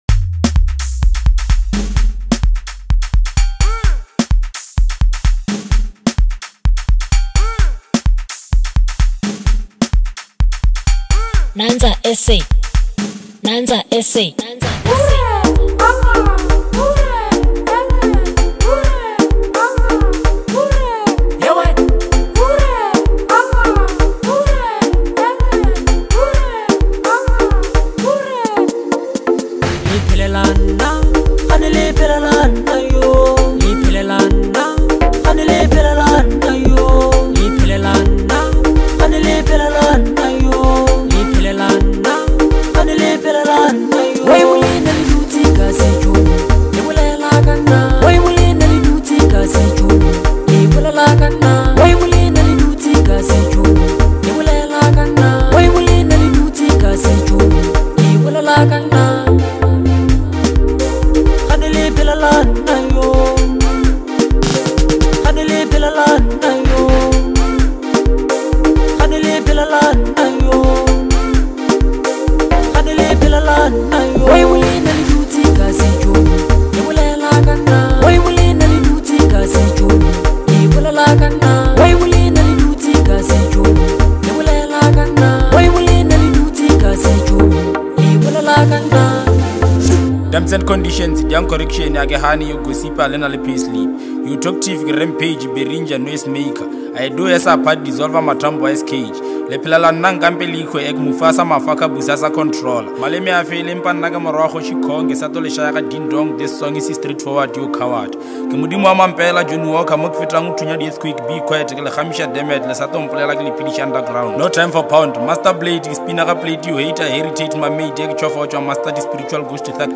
heartfelt and emotional track